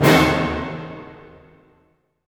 Index of /90_sSampleCDs/Roland LCDP08 Symphony Orchestra/HIT_Dynamic Orch/HIT_Orch Hit Dim
HIT ORCHDI04.wav